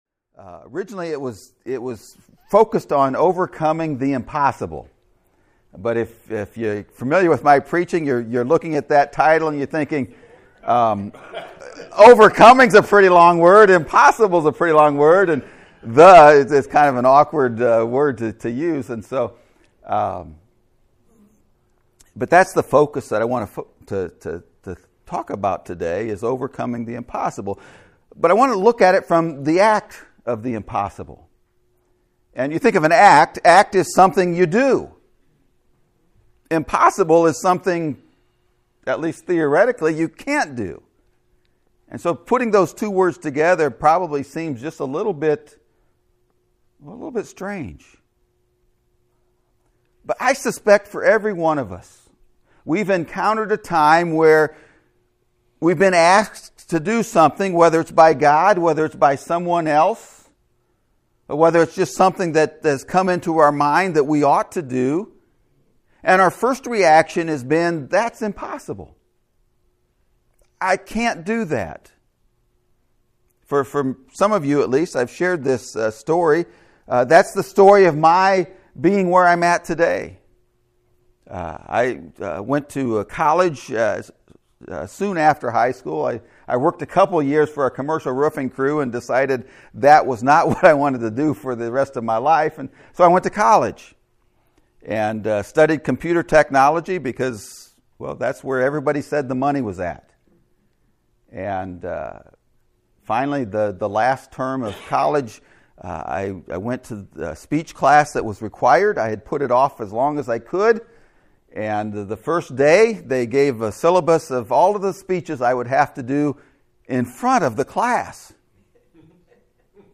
The ACT Of Impossible (Sermon Audio)